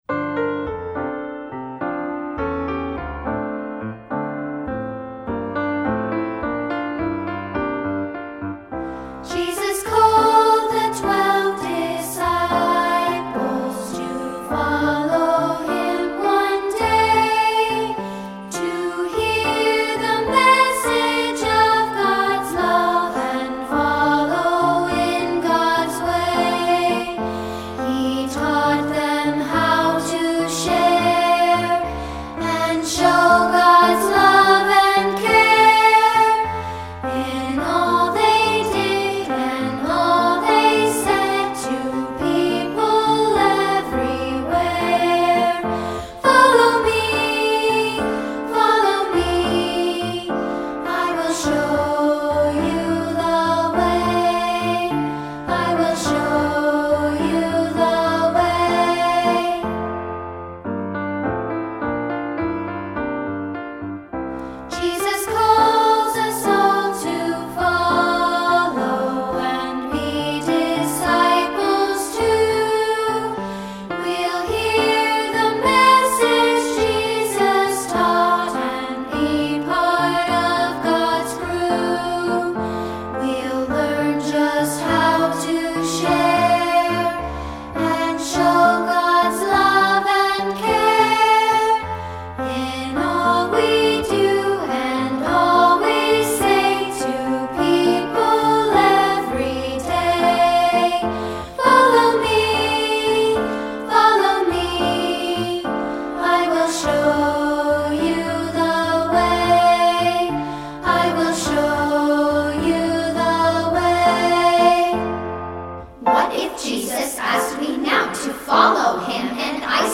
Unison with piano